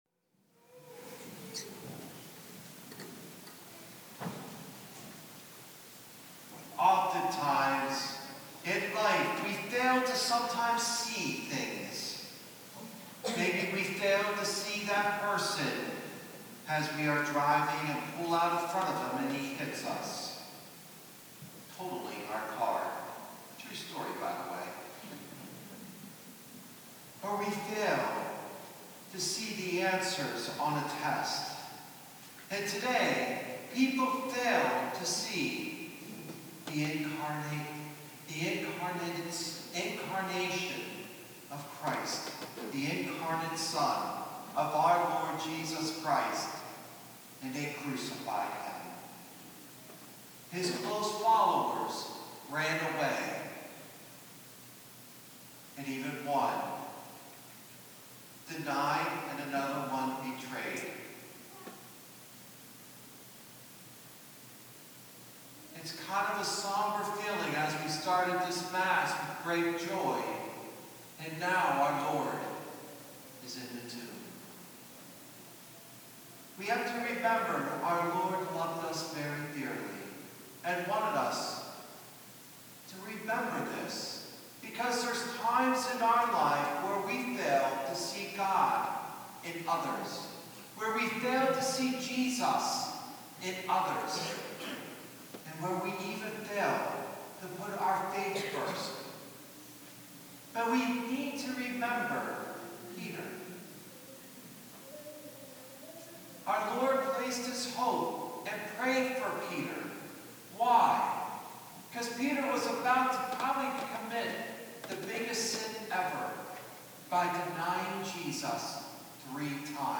Palm Sunday